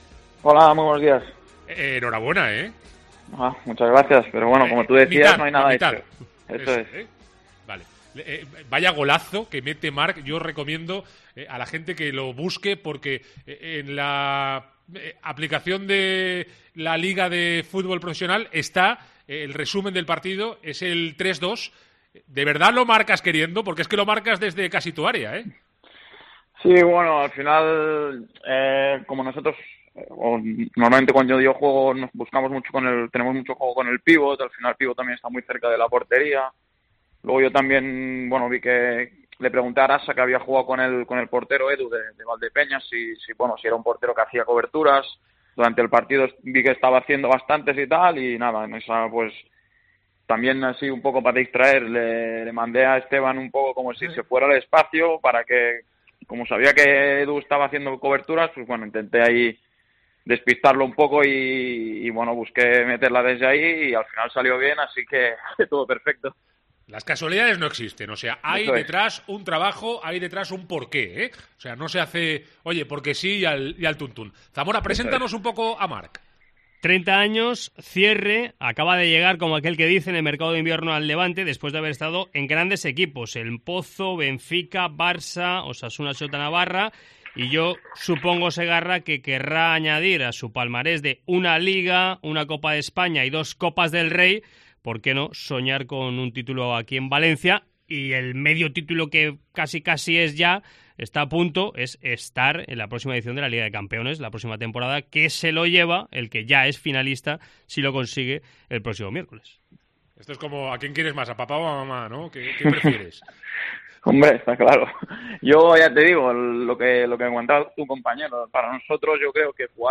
AUDIO. Entrevista